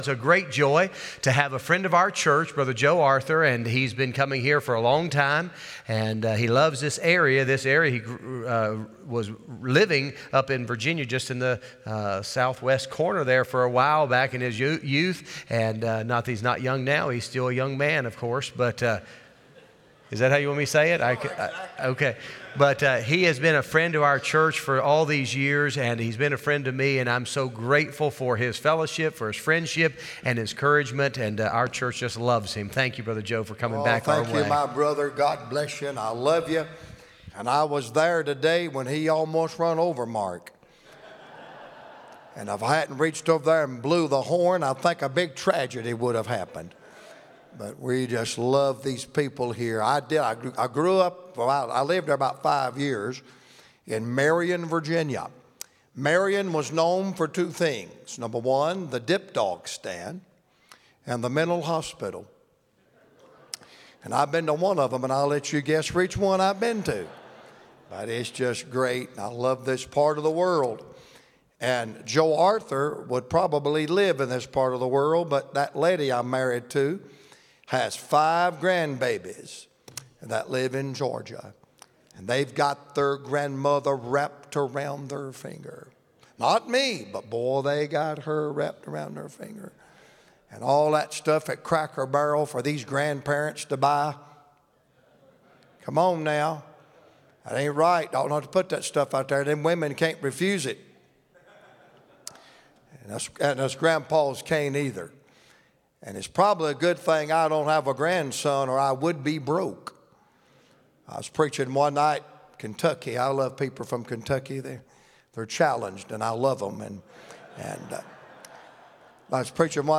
Revival Service